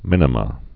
(mĭnə-mə)